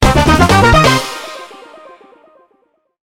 Raise.wav